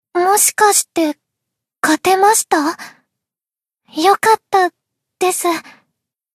贡献 ） 分类:蔚蓝档案语音 协议:Copyright 您不可以覆盖此文件。
BA_V_Tsukuyo_Tactic_Victory_1.ogg